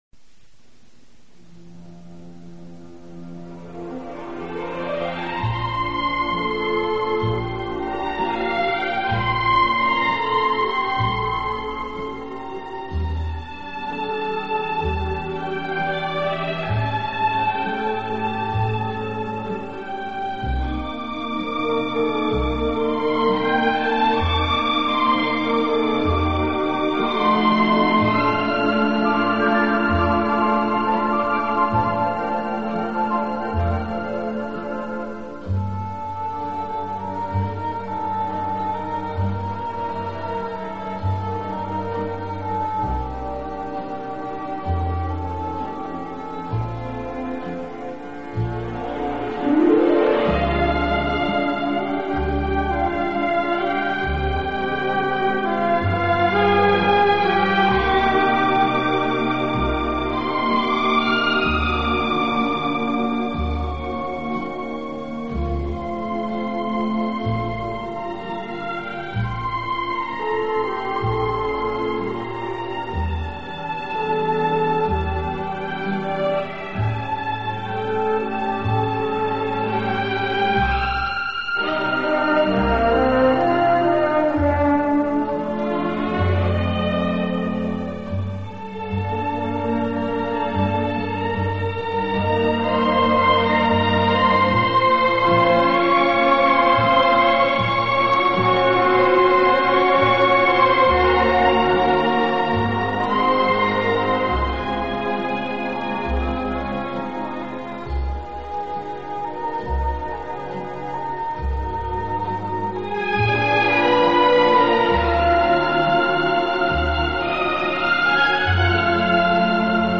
轻快、节奏鲜明突出，曲目以西方流行音乐为主。